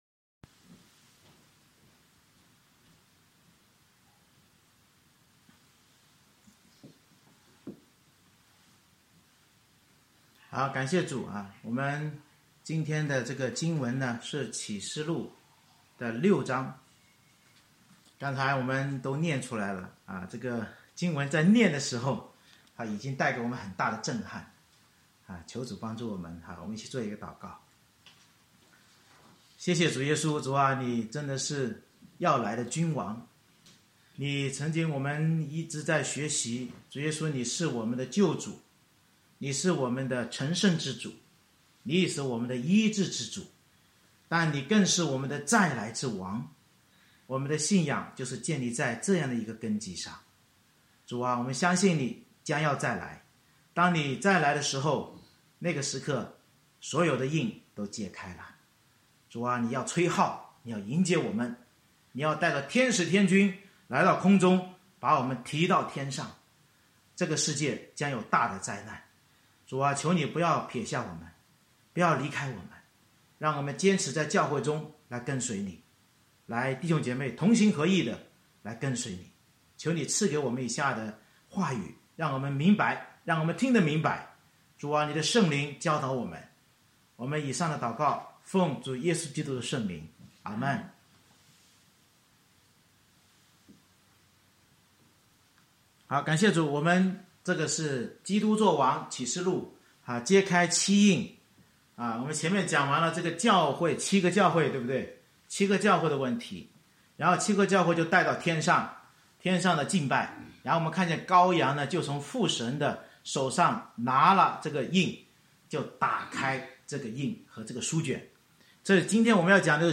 May 16, 2021 揭开七印（上）—世界末日的真相 Series: 《启示录》讲道系列 Passage: 启示录6章 Service Type: 主日崇拜 约翰看见羔羊揭开六印所引发天灾人祸，警醒我们世界大灾难和基督再临之日关键看教会受苦中 是否能持守真道并忍耐到底直到圣徒的人数满足。